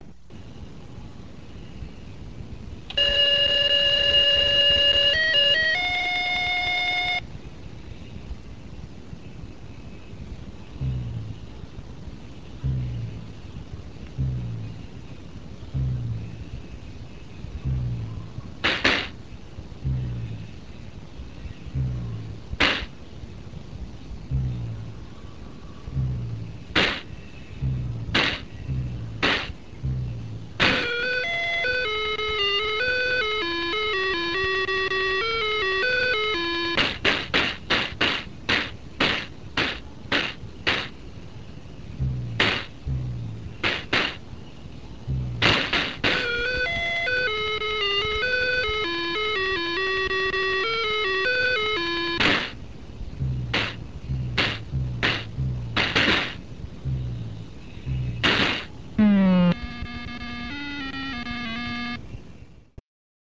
ジャンプの音と着地ミス音がニセものに比べて上品です。